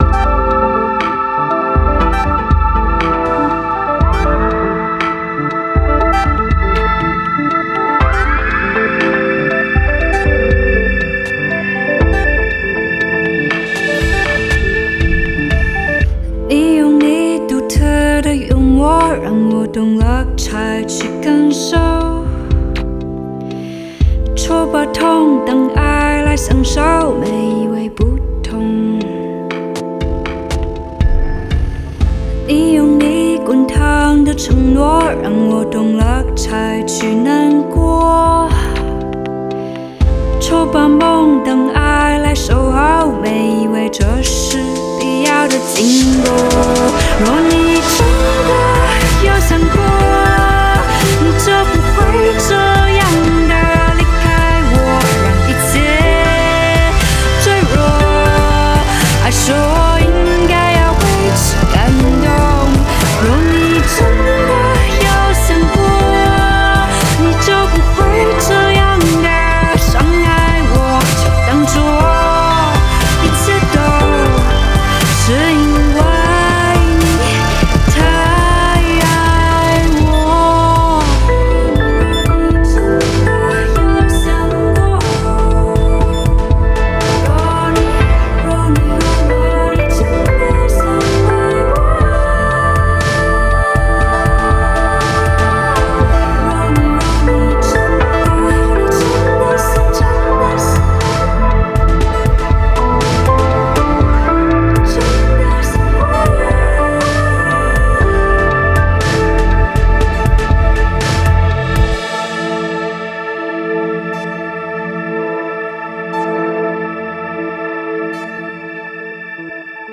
BPM120
Audio QualityMusic Cut
Some nice and chill indietronica song.